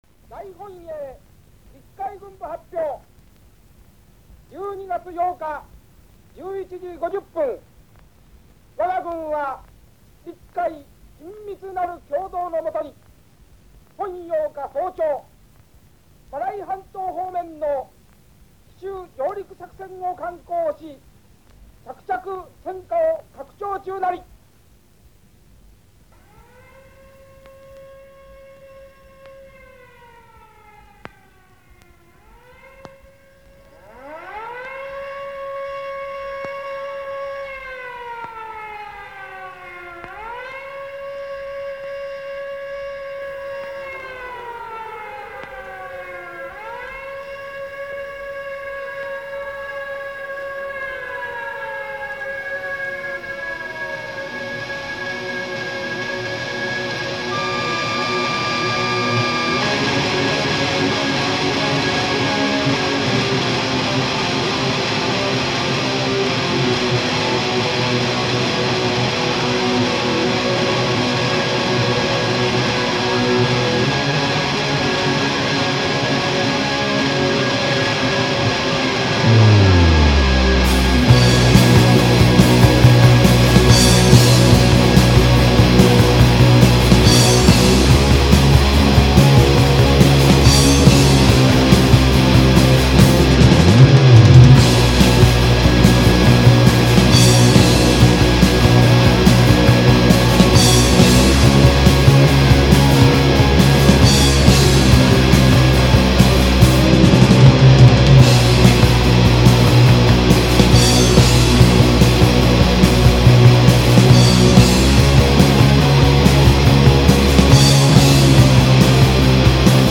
まぁその道の人以外はタダのノイズにしか聞こえないと思いますが。。